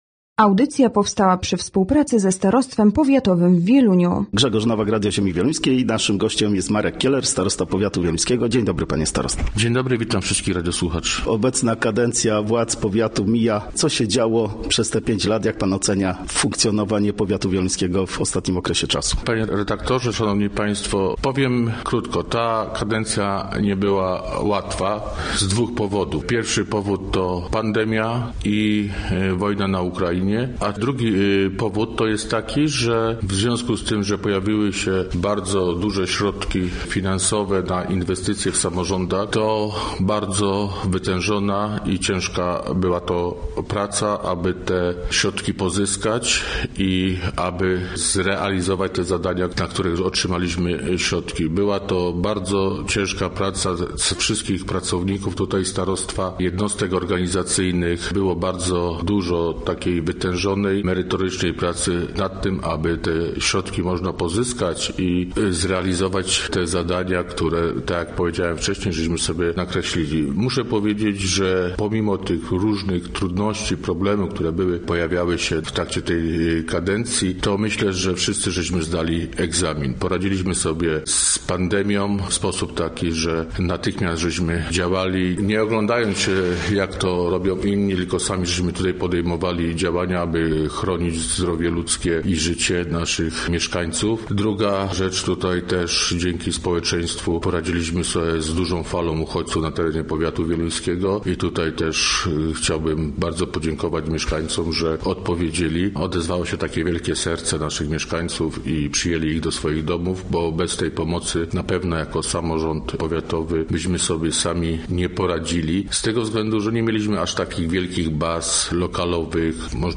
Gościem Radia ZW był Marek Kieler, starosta powiatu wieluńskiego
Podsumowanie upływającej kadencji samorządu, inwestycji i wyzwania związane z wojną za wschodnią granicą czy pandemią Covid 19 oraz finanse powiatu wieluńskiego były tematem naszej rozmowy z Markiem Kielerem, starostą powiatu wieluńskiego.